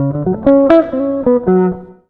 160JAZZ  3.wav